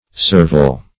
surveyal - definition of surveyal - synonyms, pronunciation, spelling from Free Dictionary Search Result for " surveyal" : The Collaborative International Dictionary of English v.0.48: Surveyal \Sur*vey"al\, n. Survey.
surveyal.mp3